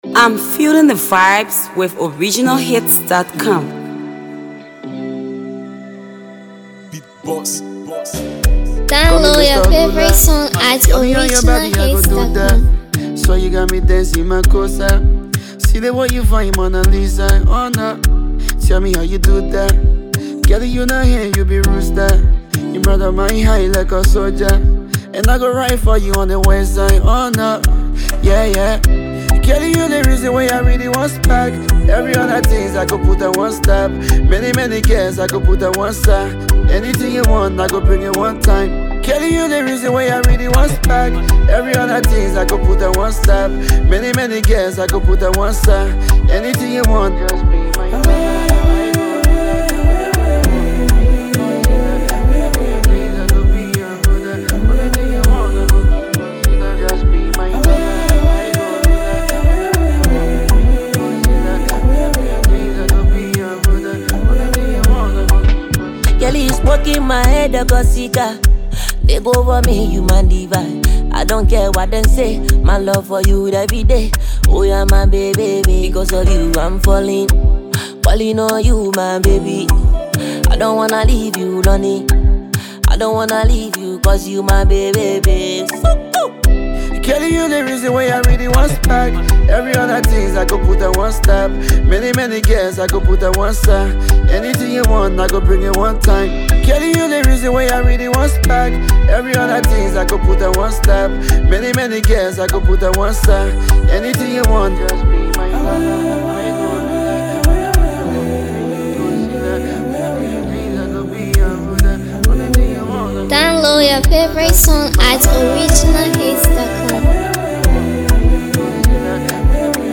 praising its soft melody and carefully crafted lyrics.